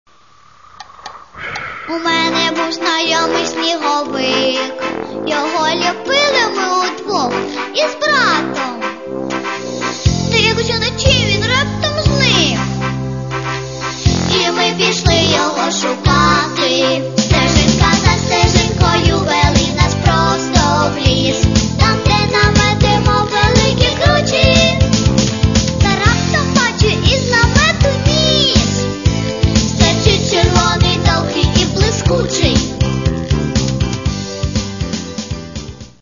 Для детей